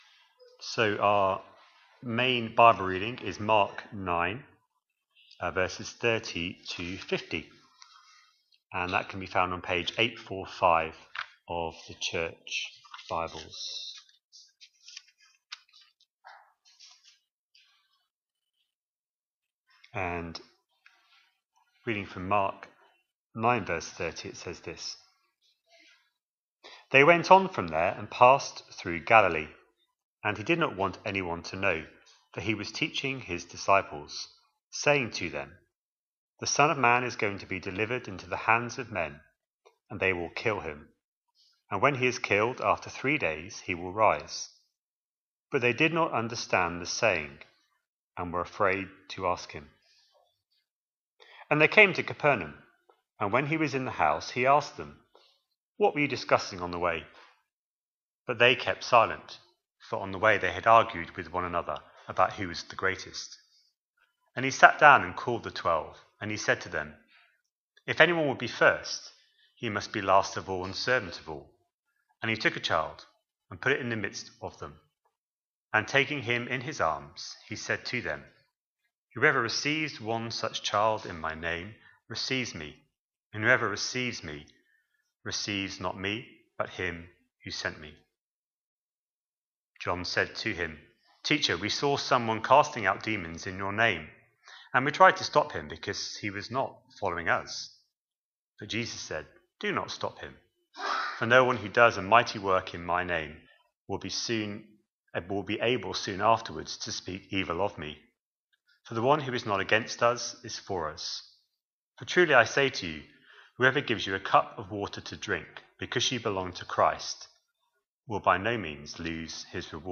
A sermon preached on 11th January, 2026, as part of our Mark 25/26 series.